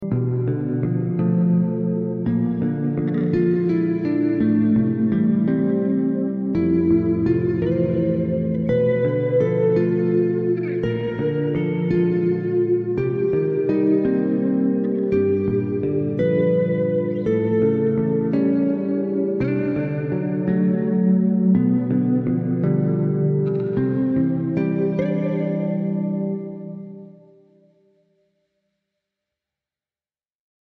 2 heavenly Basses and a sound effects free download